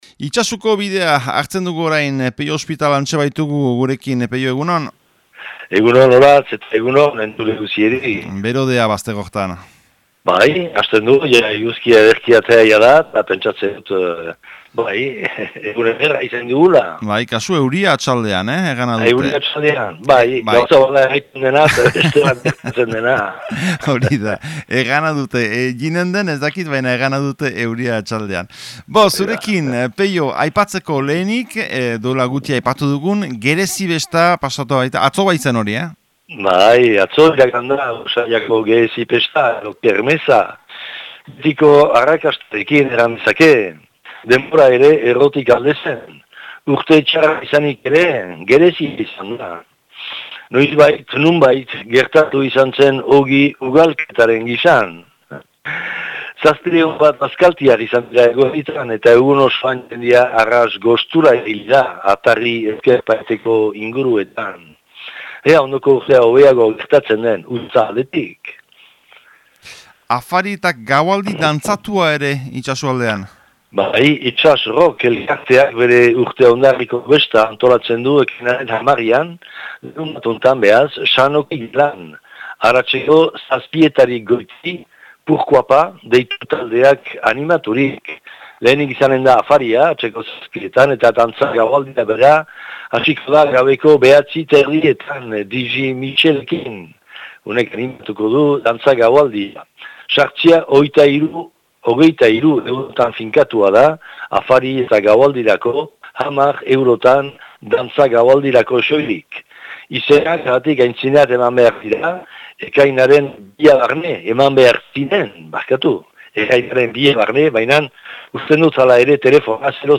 Ekainaren 5eko Itsasuko berriak